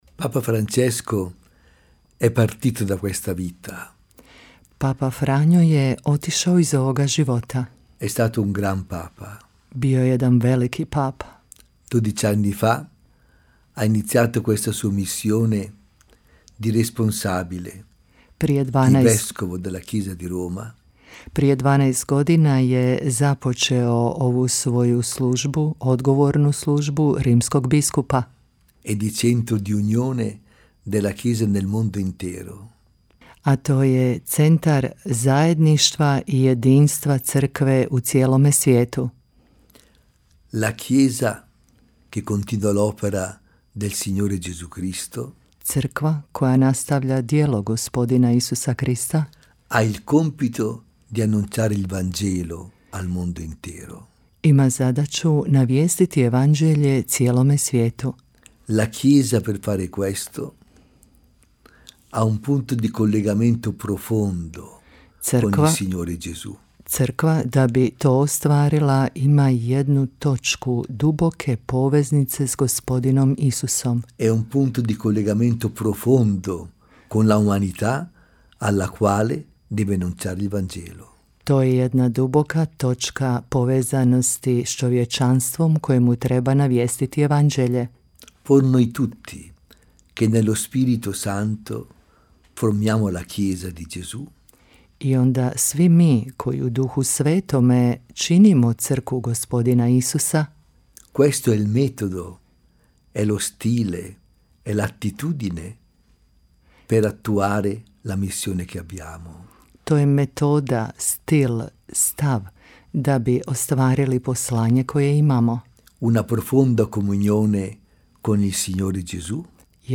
O životu, naslijeđu i duhovnom značaju Svetog Oca Franje s posebnim naglaskom na Međugorje i svemu što je učinio za ovo mjesto milosti u za Radiopostaju Mir Međugorje govorio je apostolski vizitator s posebnom ulogom za župu Međugorje nadbiskup Aldo Cavalli, čije obraćanje u nastavku donosimo u cijelosti, a možete ga poslušati i u audiozapisu.